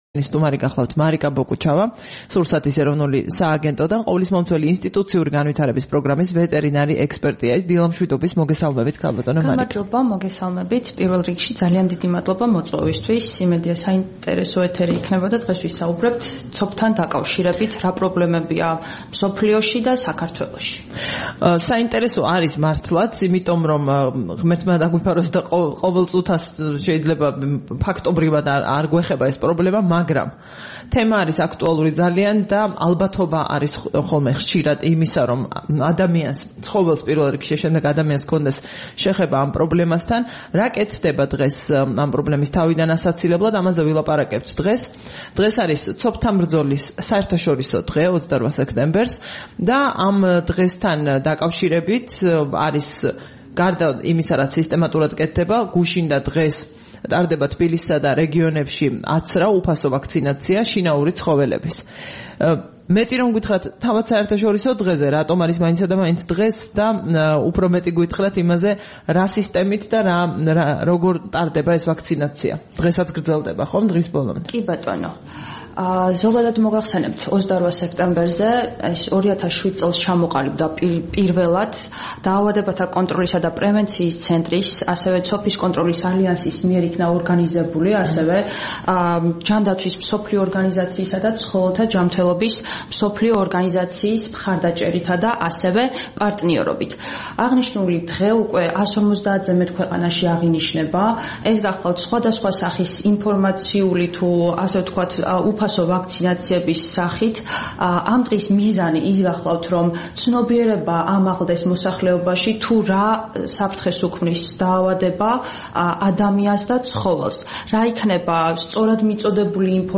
28 სექტემბერს რადიო თავისუფლების დილის გადაცემის სტუმარი იყო სურსათის ეროვნული სააგენტო